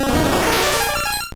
Cri de Paras dans Pokémon Or et Argent.